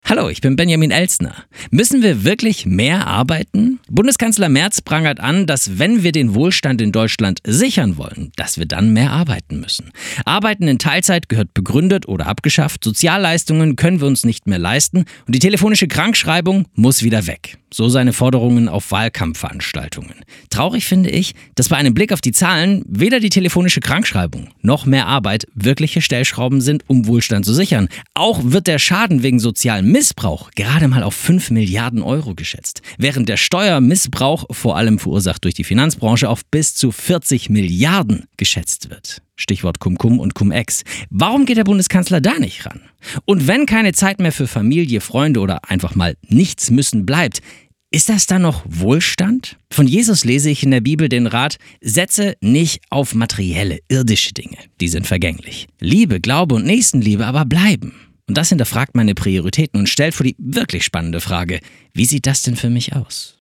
Startseite > andacht > 60-Stunden-Woche